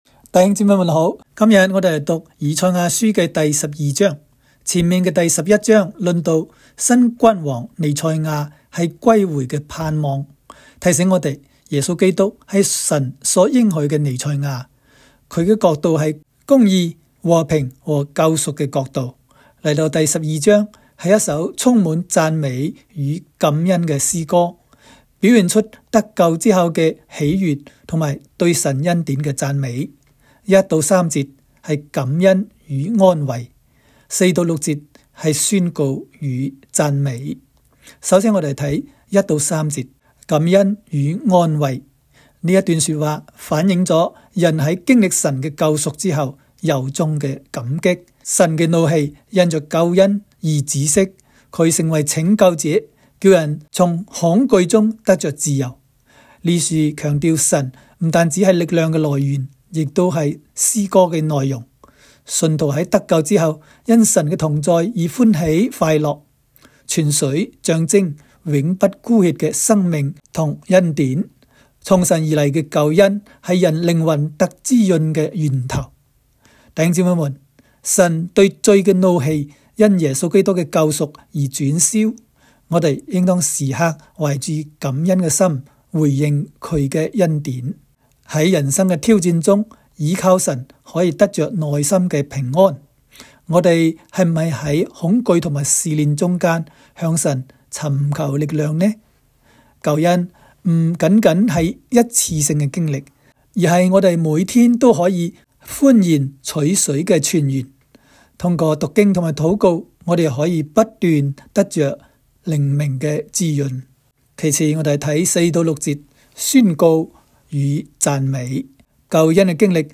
赛12（讲解-粤）.m4a